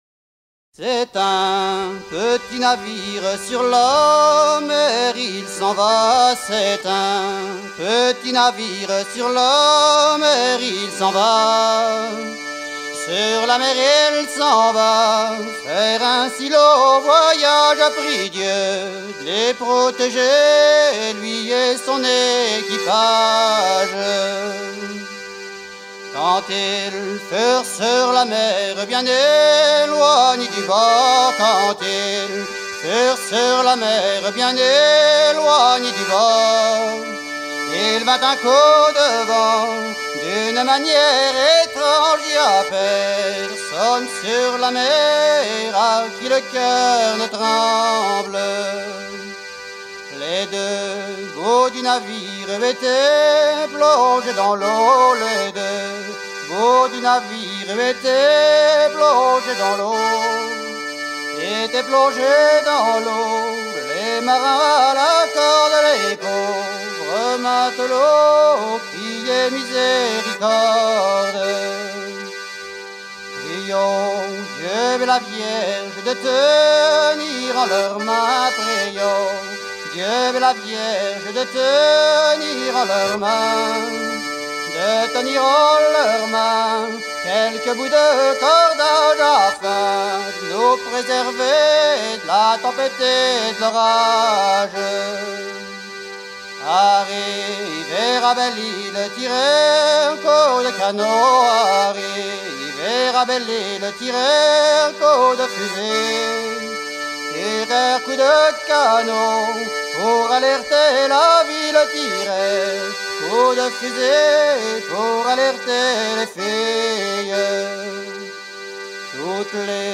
Version recueillie dans le golfe du Morbihan vers 1980
Genre strophique
Chants de marins en fête - Paimpol 1999